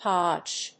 Hodge /hάdʒhˈɔdʒ/
音節hodge発音記号・読み方hɑ́ʤ|hɔ́ʤ